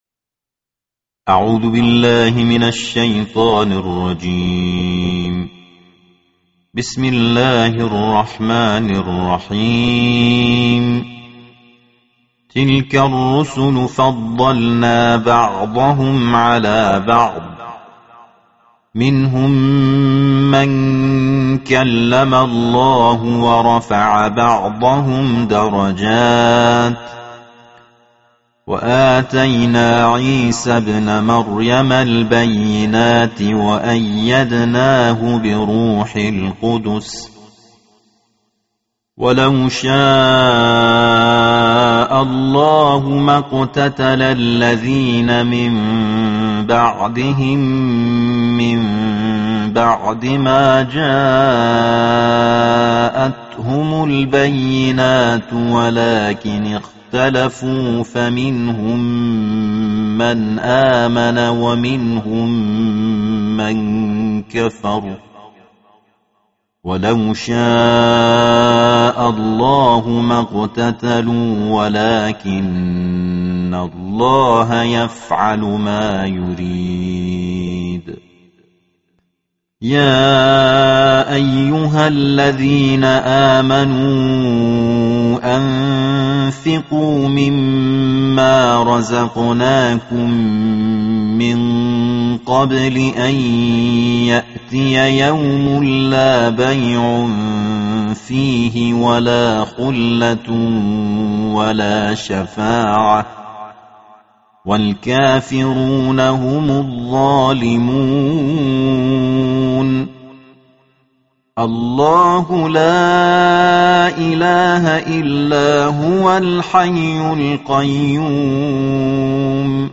रमज़ान के पवित्र महीने के तीसरे दिन पवित्र कुरान के तीसरे भाग का पाठ पेश किया जारहा है।